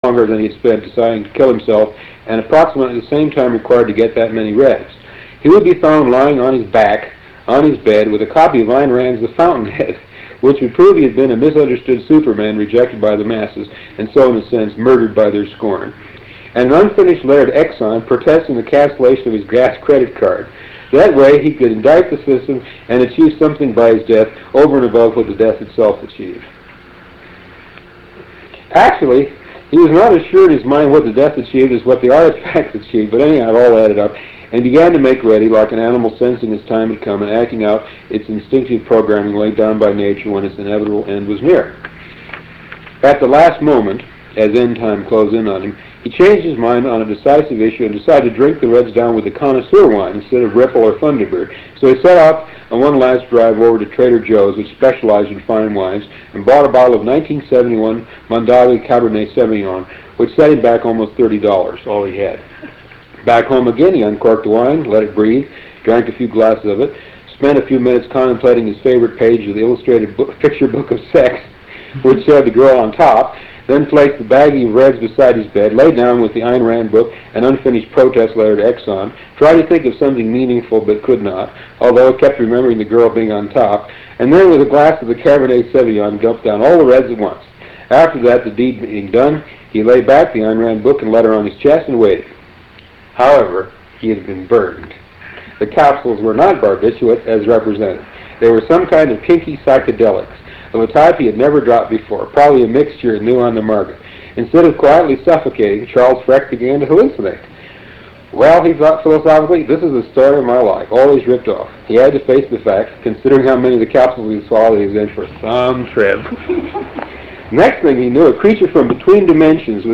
Interview with Philip K Dick 7